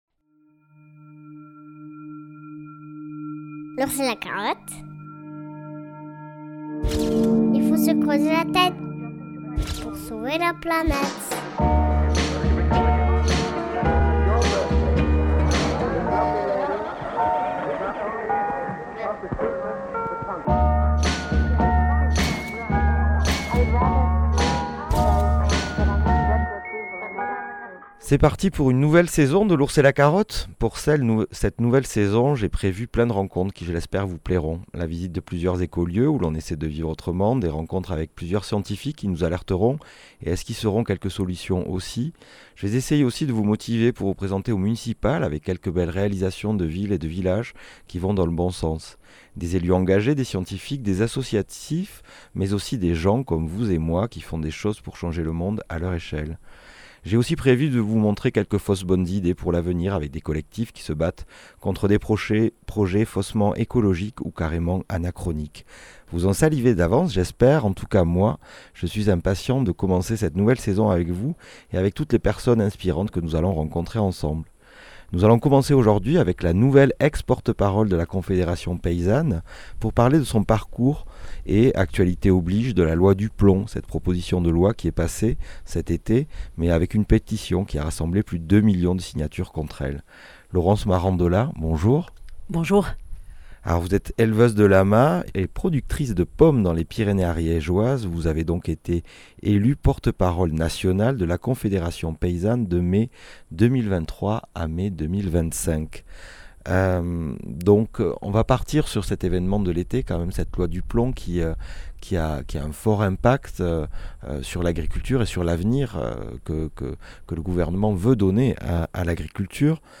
Une discussion riche et intime qui invite à découvrir une personnalité singulière et passionnée.